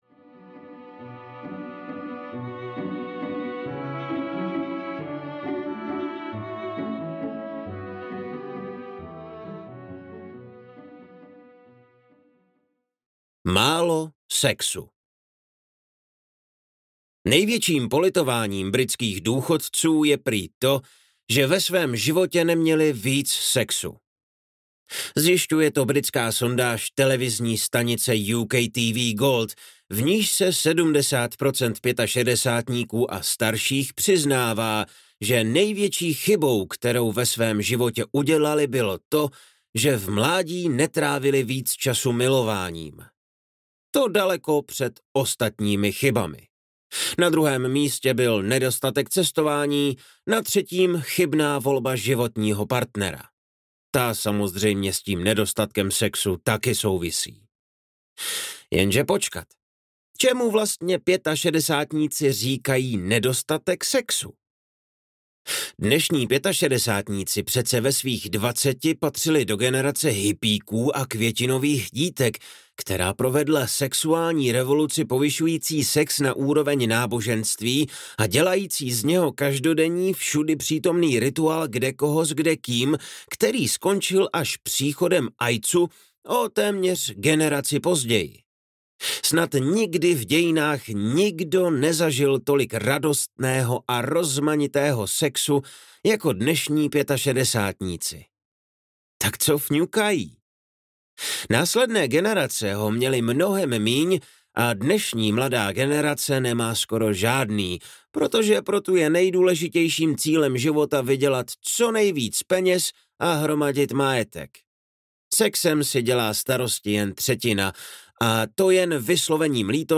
MAO sexu – Jak si blaho odpíráme audiokniha
Ukázka z knihy